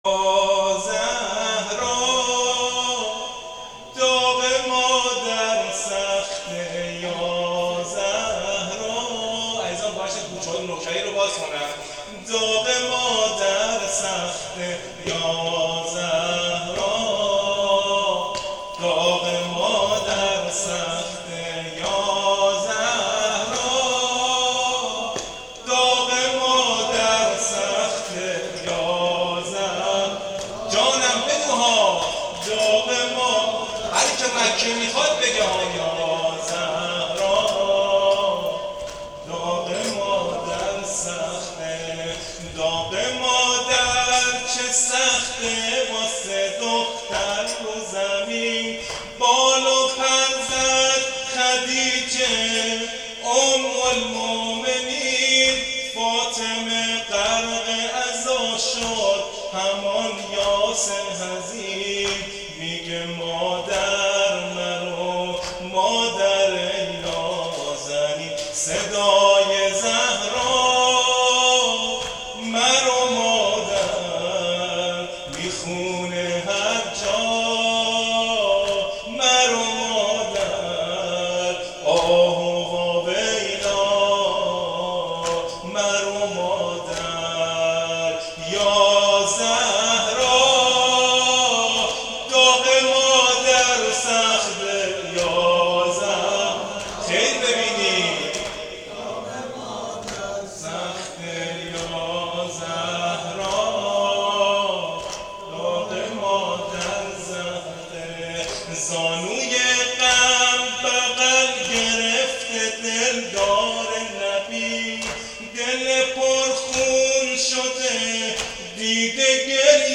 نوحه وفات حضرت خدیجه سلام الله علیها